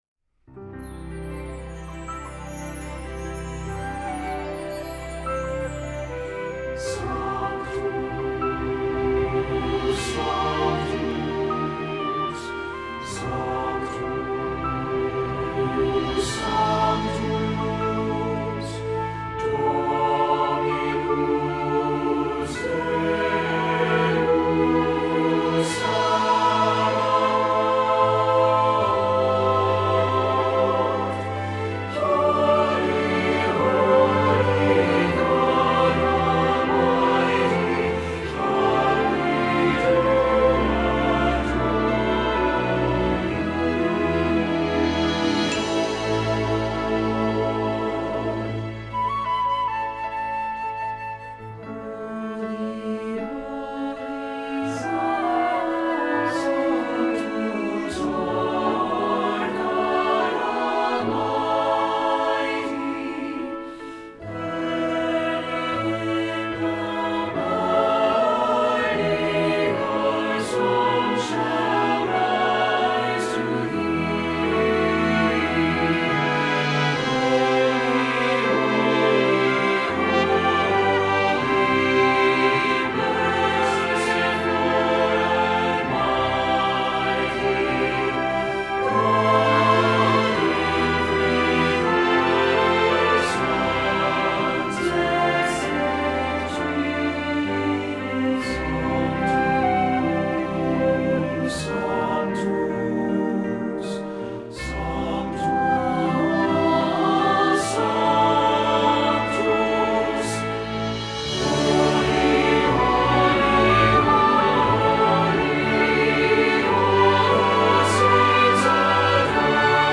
Choral Church
SATB